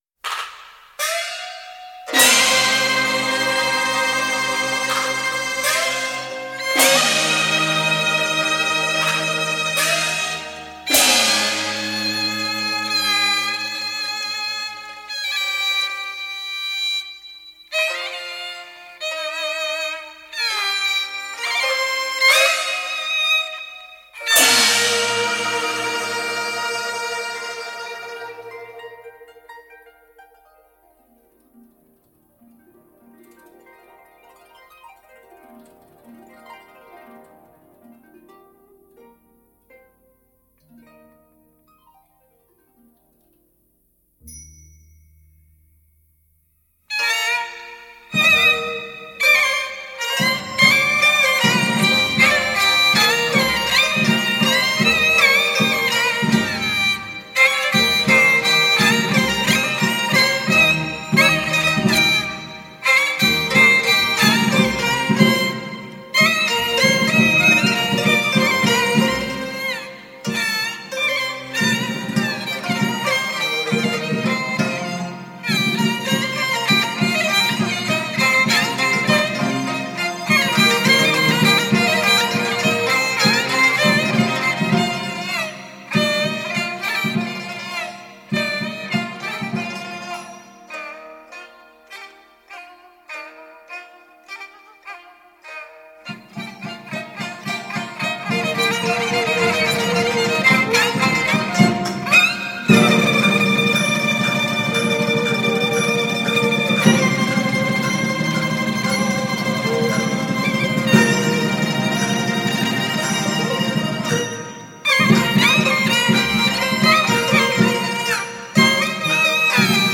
这两种乐器都以爽健、开朗取胜，但有时又不失细腻之处。
以京胡领奏铿锵硬朗、刚劲有力，别有一种古典式英雄气概。
京胡与乐队
京剧曲牌